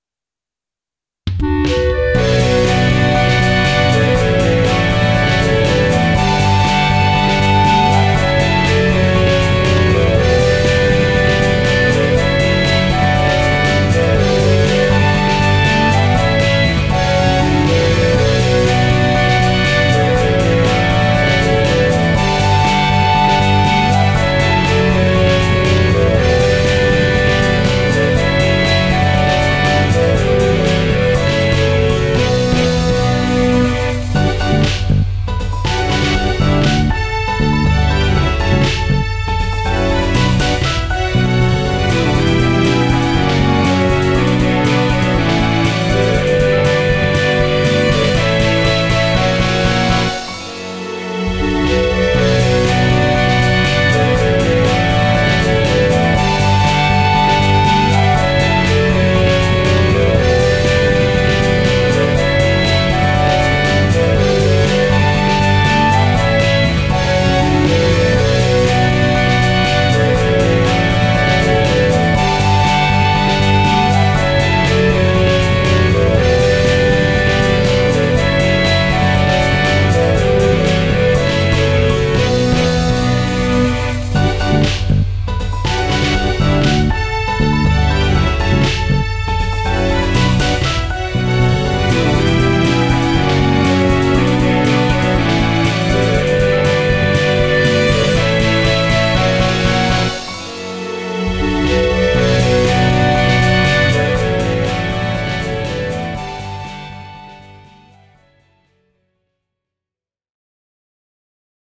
【切ない】【情熱的】